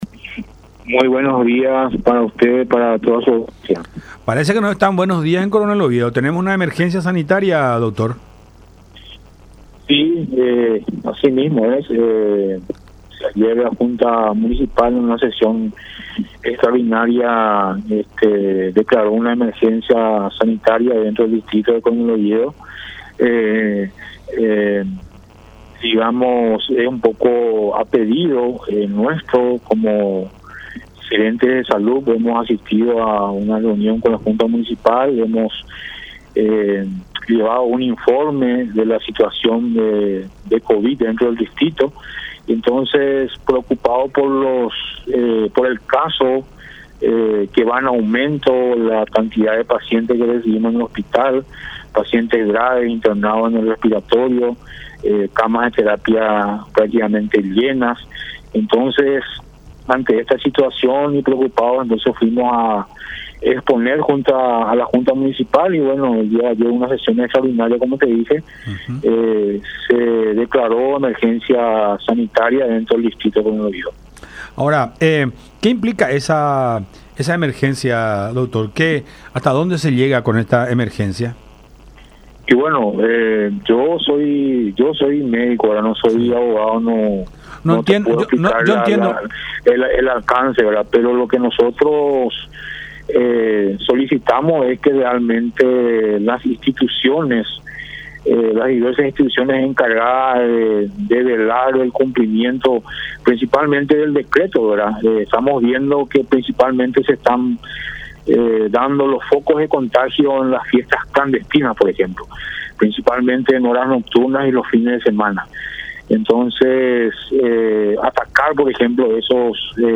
en contacto con La Unión R800 AM